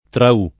Traù [ tra 2+ ] top. (Dalm.) — non Trau